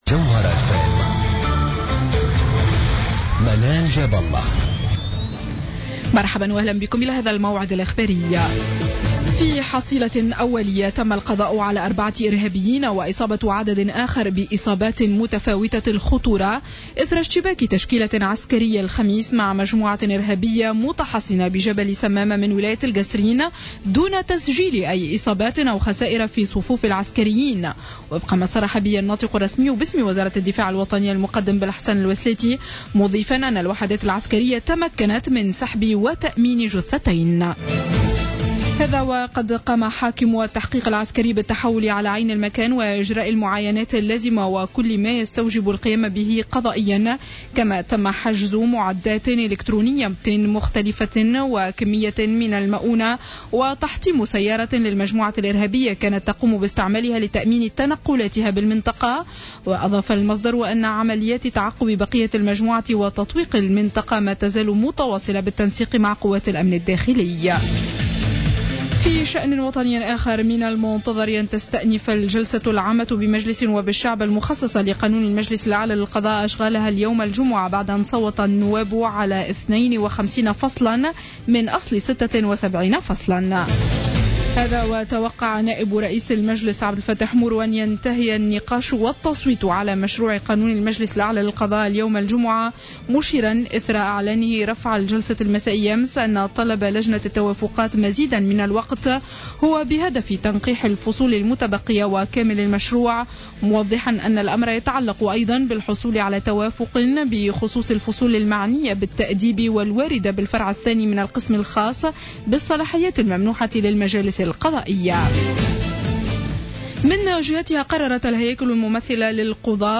نشرة أخبار منتصف الليل ليوم الجمعة 15 ماي 2015